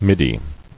[mid·dy]